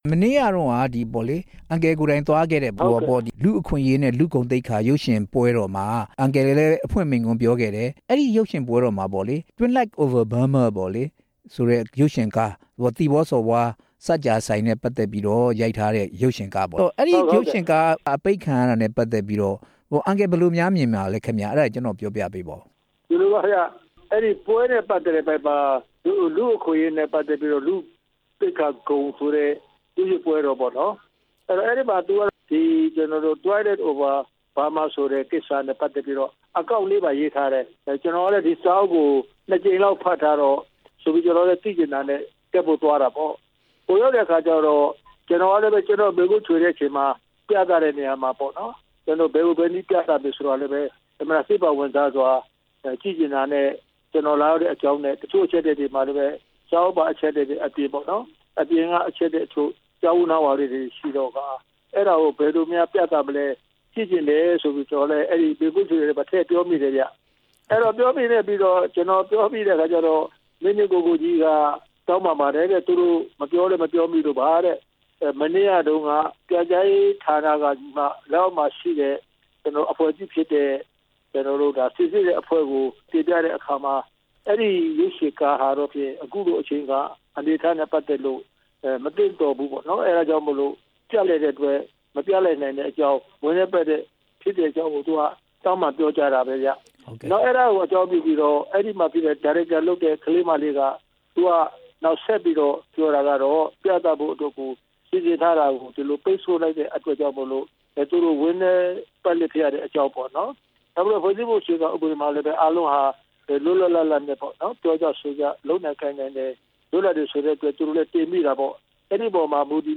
NLD နာယက ဦးတင်ဦး ကို မေးမြန်းချက်